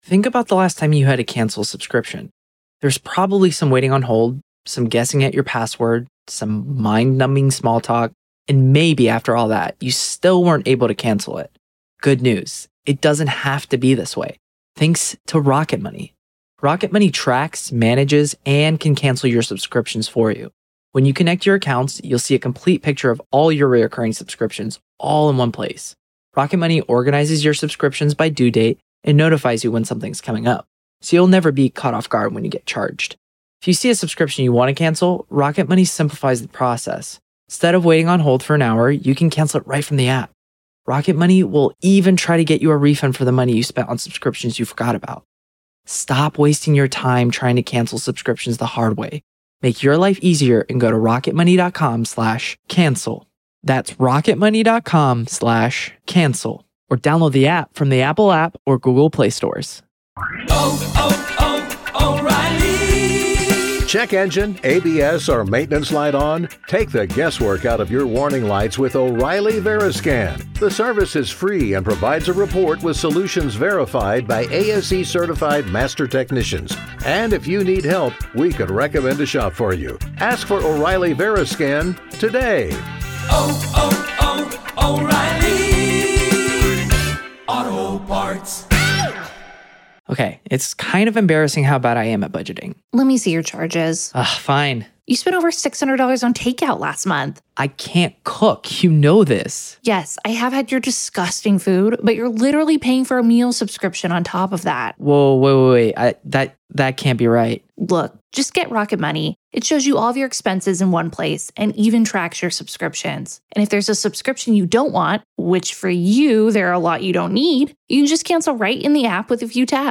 LIVE COURTROOM COVERAGE — NO COMMENTARY
There is no editorializing, no added narration, and no commentary — just the court, the attorneys, the witnesses, and the judge.